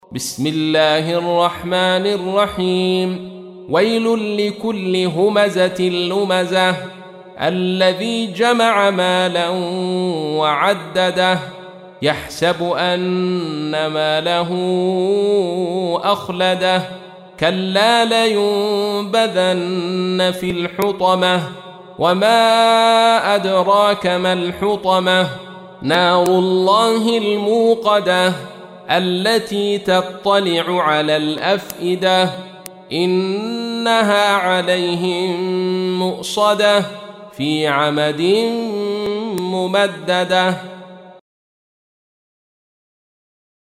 تحميل : 104. سورة الهمزة / القارئ عبد الرشيد صوفي / القرآن الكريم / موقع يا حسين